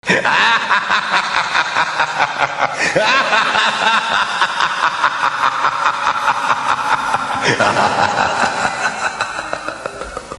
Evil Laugh 3.mp3